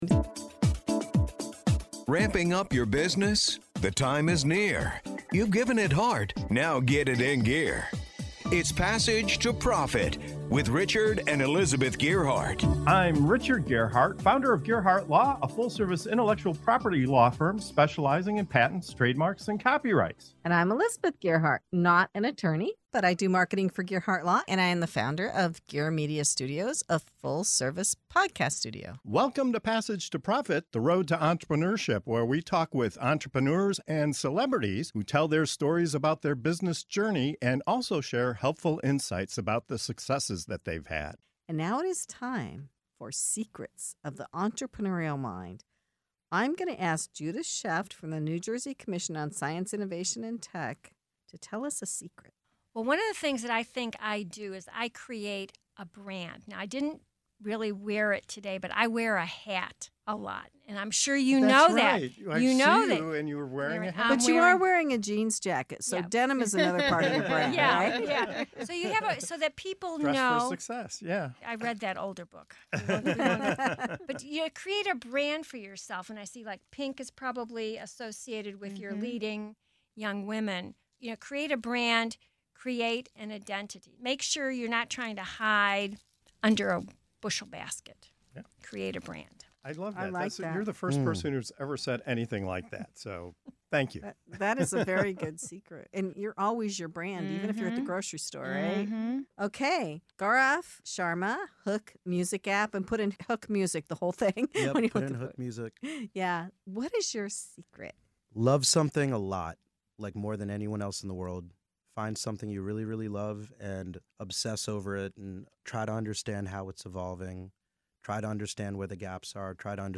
In this inspiring segment of of "Secrets of the Entrepreneurial Mind" on Passage to Profit Show, our dynamic guests share the personal philosophies that fuel their success. From building a bold personal brand to obsessing over what you love, embracing collaboration, cultivating strong habits, and adapting to constant change—these bite-sized secrets offer powerful takeaways for anyone on the entrepreneurial journey.